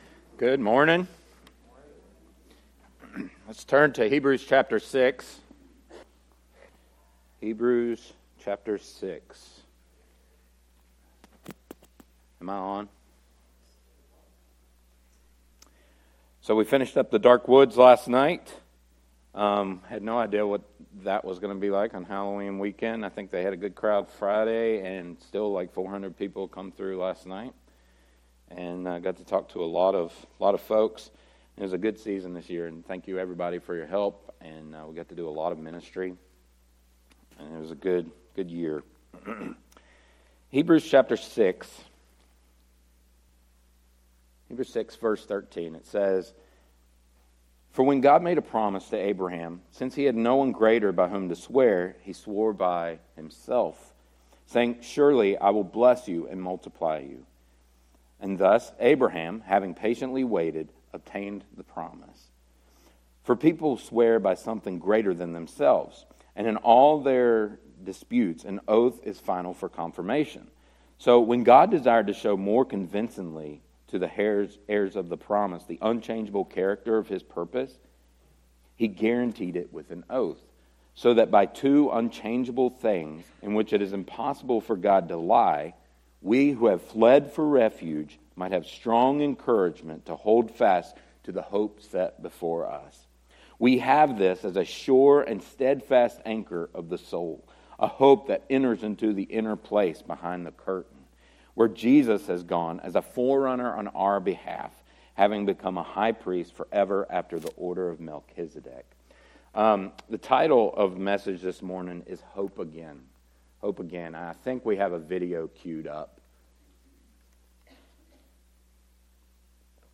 sermon-audio-trimmed.mp3